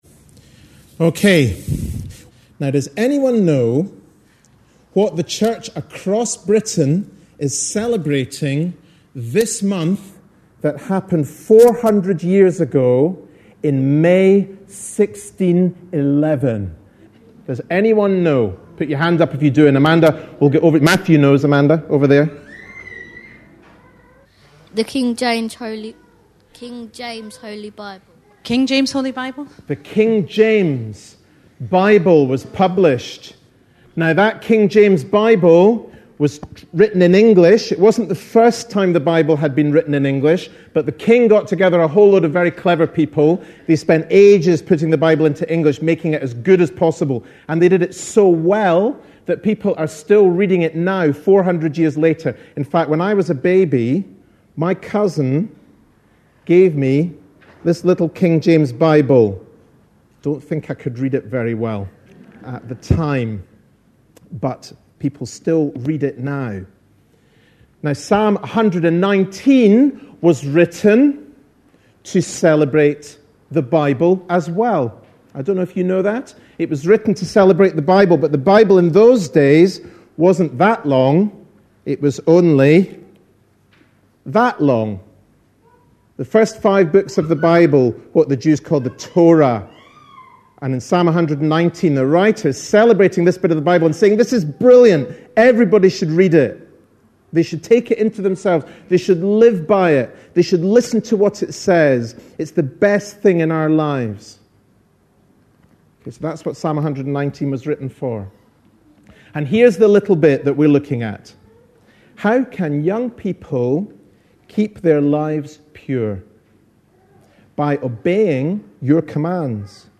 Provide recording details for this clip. This was a family service, which included the songs Jesus' Love is Very Wonderful', Wonderful Lord', and `For God So Loved the World' earlier.